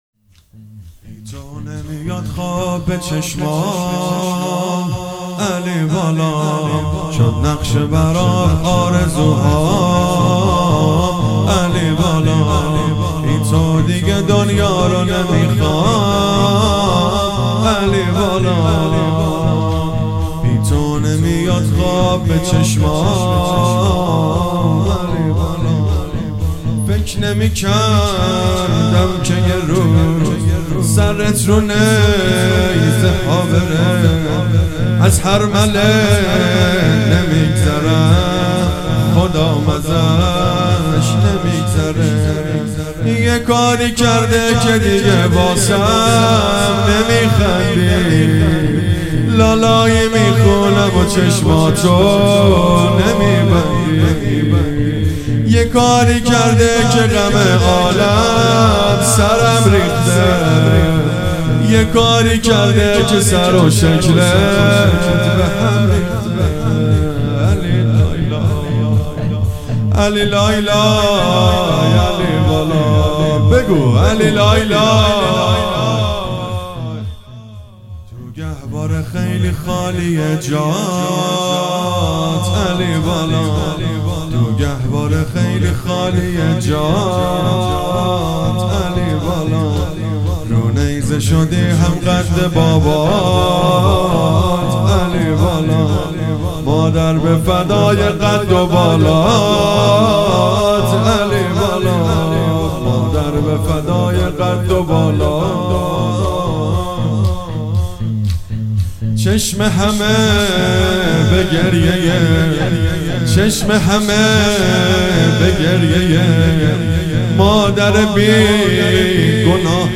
هیئت جوانان حضرت علی اکبر (ع)